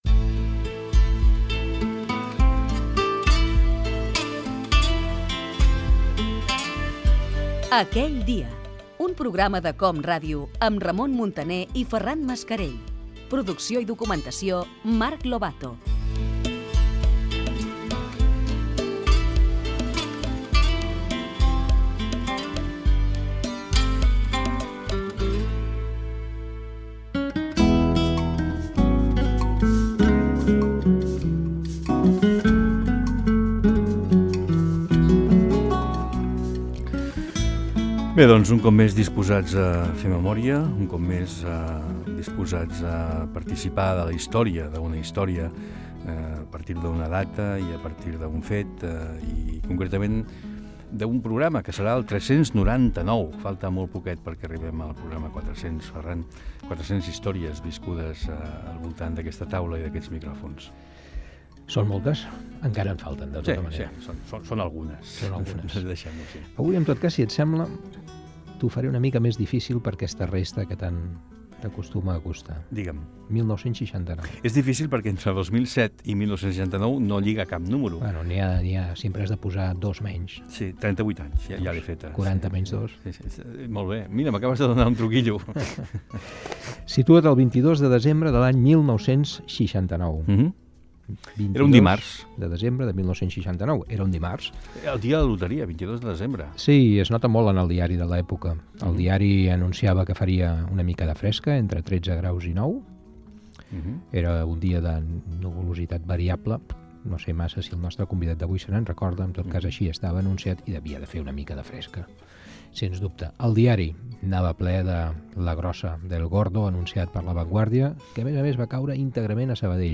Careta del programa, presentació, contextualització de la data i entrevista Gènere radiofònic Entreteniment